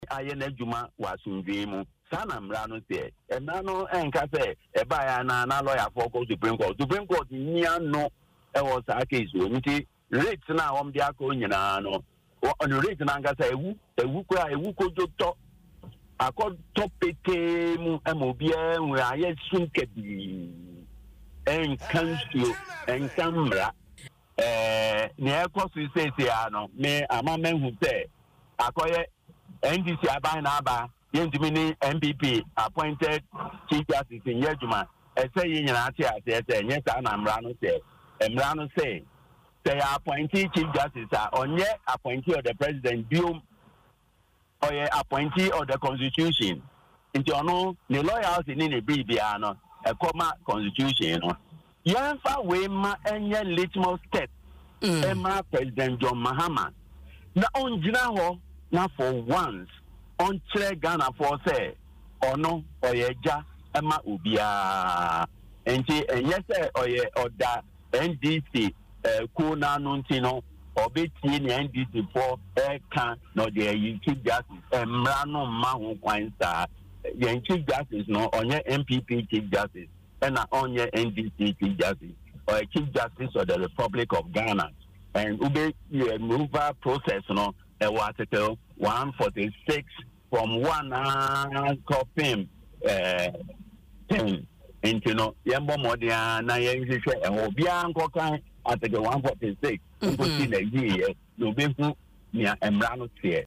Speaking in an interview on Adom FM’s Dwaso Nsem, he argued that the Supreme Court has no jurisdiction over the matter, as there are clear constitutional procedures to follow.